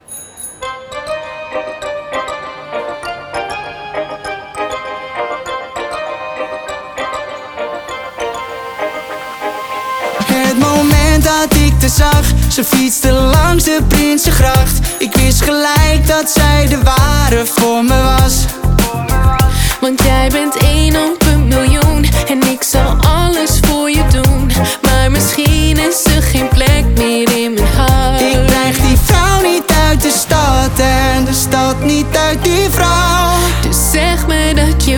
Жанр: Поп музыка / Танцевальные